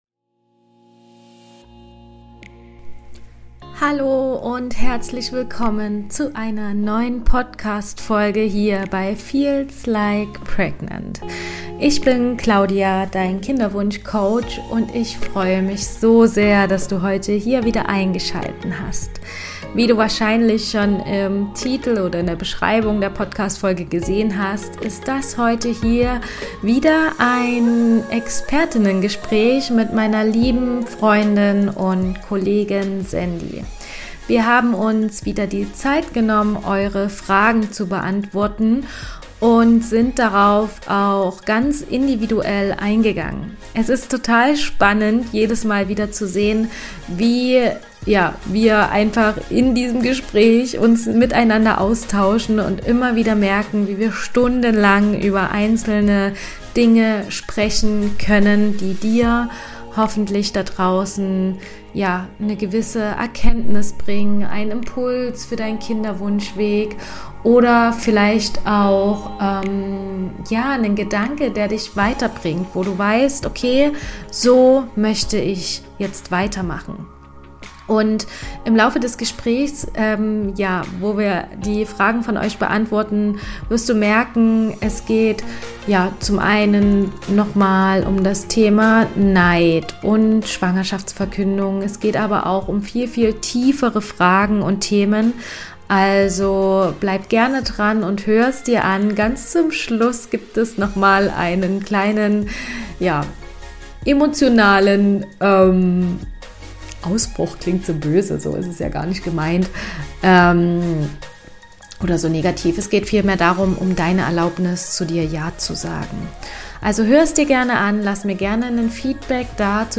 Von emotionalem Druck über Angst bis hin zu Heilung und Vertrauen hier geht’s um das, was wirklich hinter dem Kinderwunsch steckt. Eine Folge voller Empathie, Tiefe und echter Antworten von zwei Frauen, die den Weg kennen beruflich und persönlich.